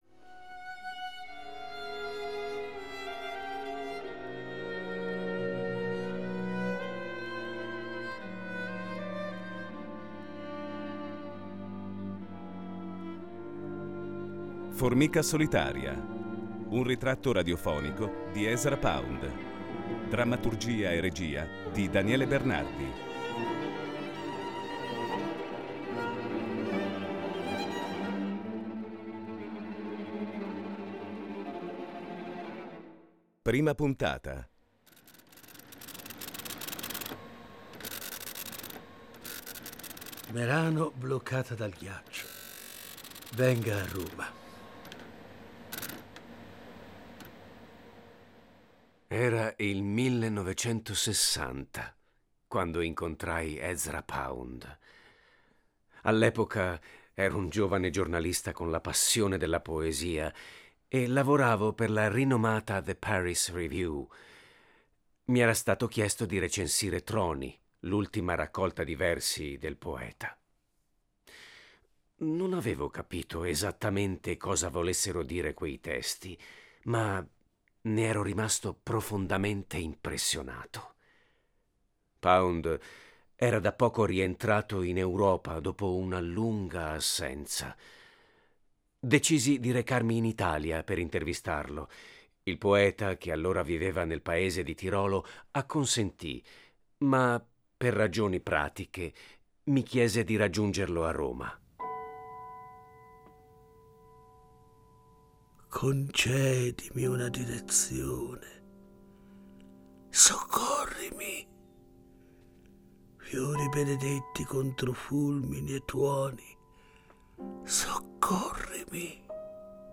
Formica solitaria. Ritratto radiofonico di Ezra Pound (1./5)